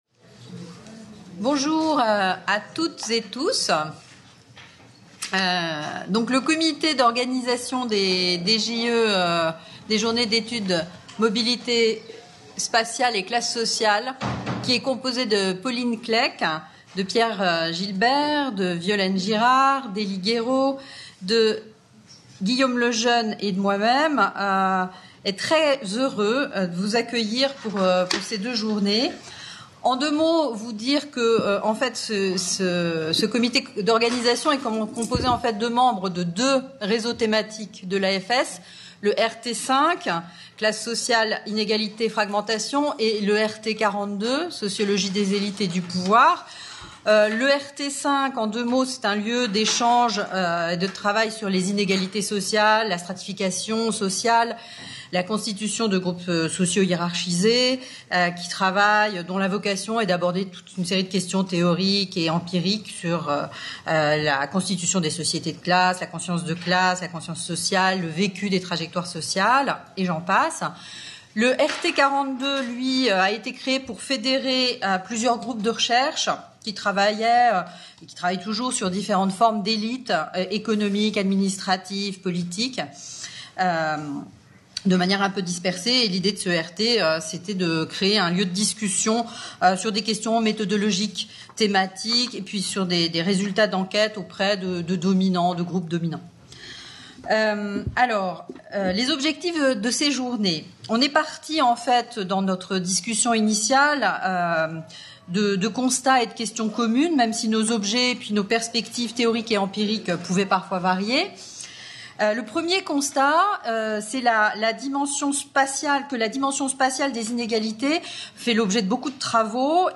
Table ronde 5/ Biopolitique, géopolitique, cosmopolitique